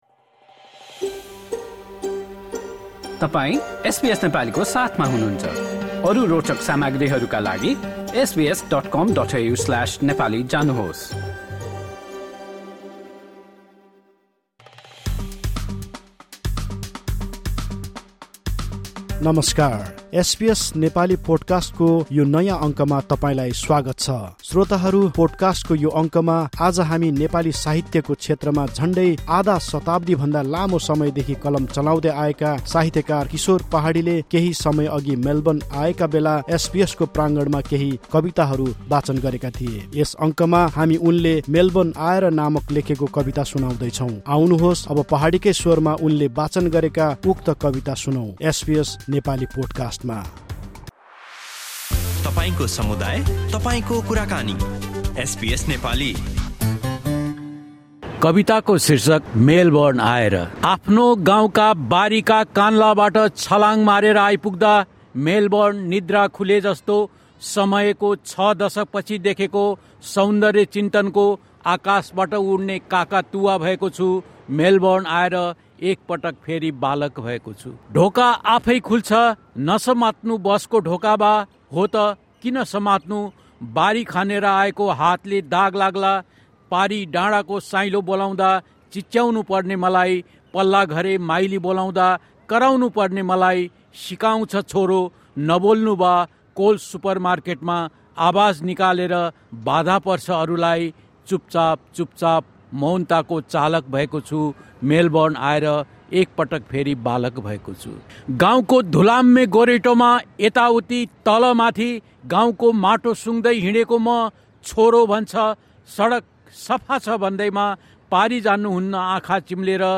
Poem: ‘After arriving in Melbourne’